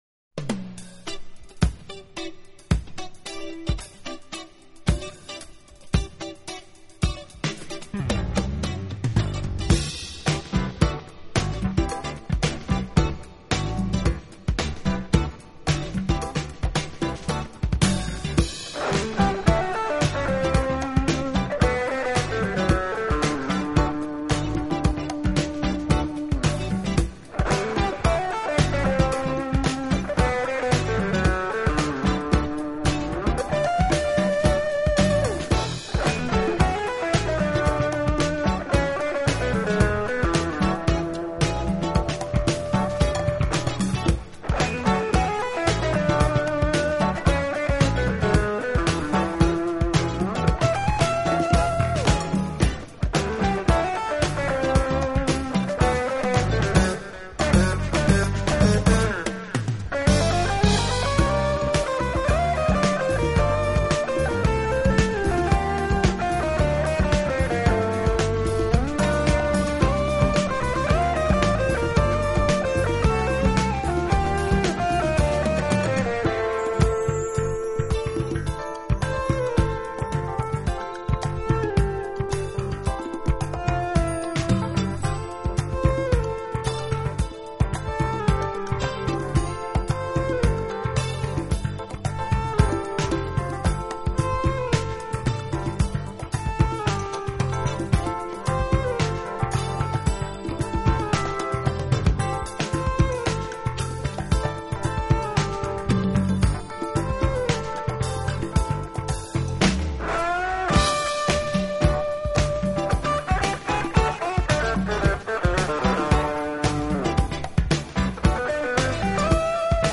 的技術、復雜的結構和激越昂揚的勁爆節奏，他只用旋律說話，把一層
又一層的優美旋律通過不同的樂器迭加到一起，產生一種奇妙的效應，